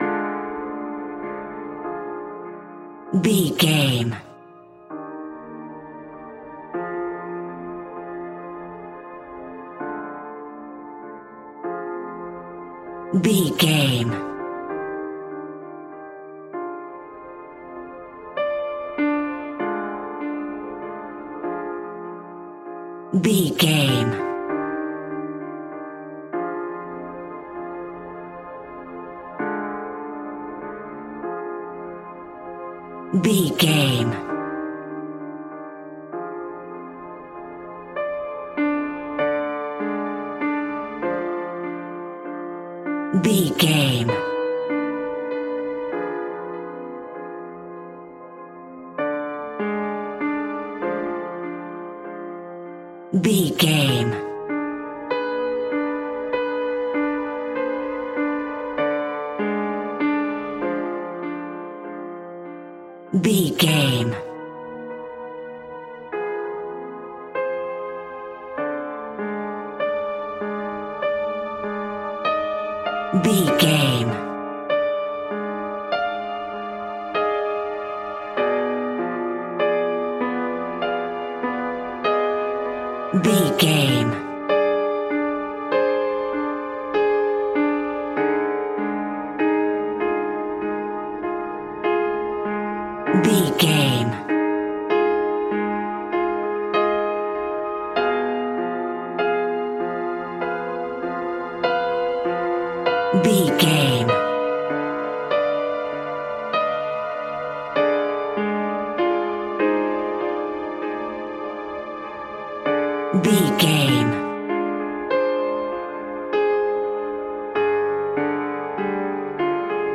Scary Piano Music Cue.
Diminished
tension
ominous
dark
suspense
haunting
eerie
strings
Acoustic Piano